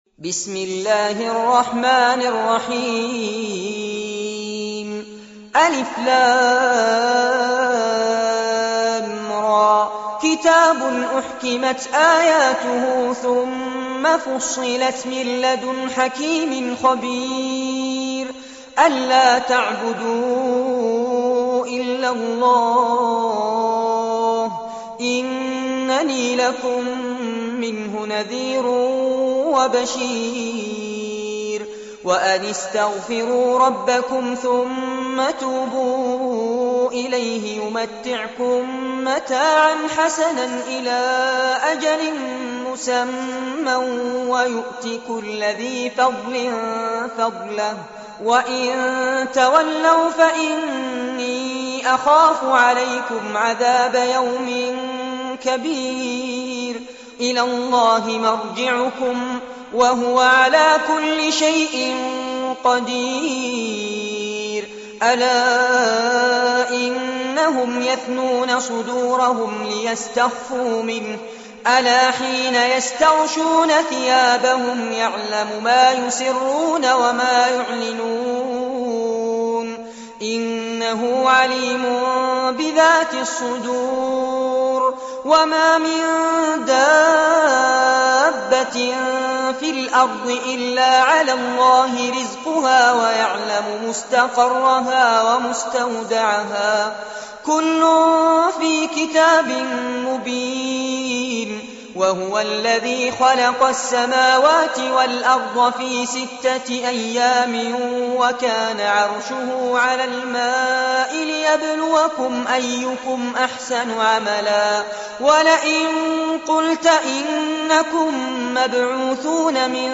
سورة هود- المصحف المرتل كاملاً لفضيلة الشيخ فارس عباد جودة عالية - قسم أغســـــل قلــــبك 2